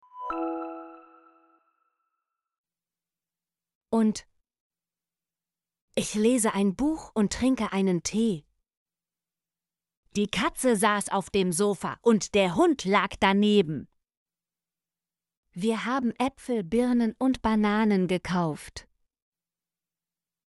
und - Example Sentences & Pronunciation, German Frequency List